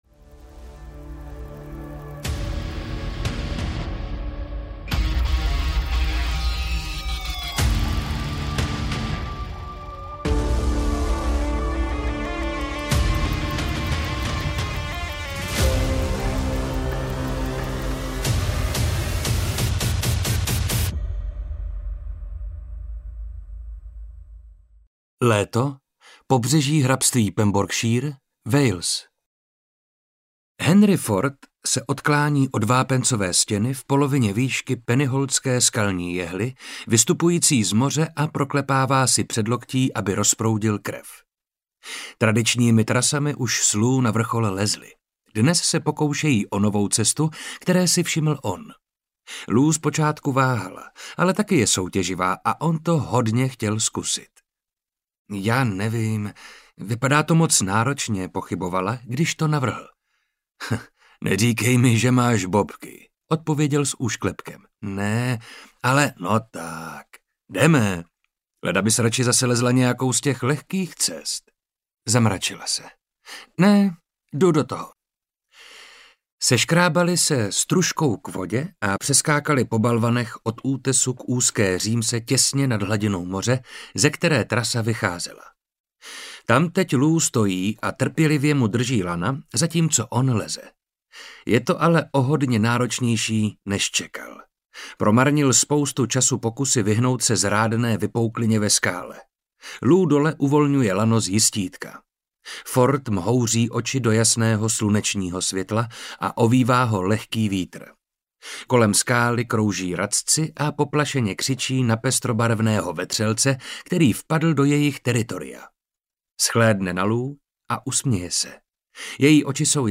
Temná hra audiokniha
Ukázka z knihy
• InterpretMarek Holý